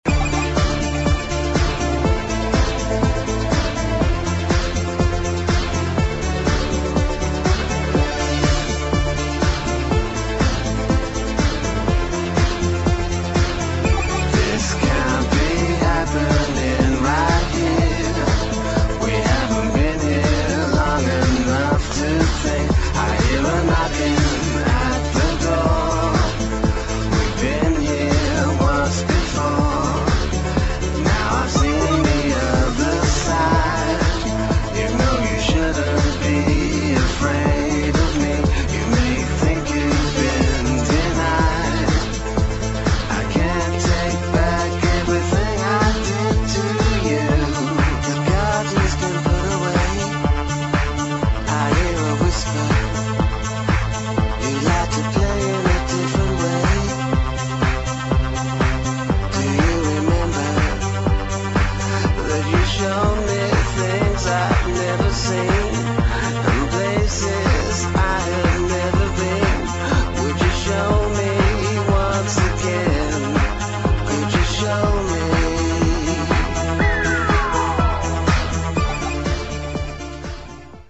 [ HOUSE | TECHNO ]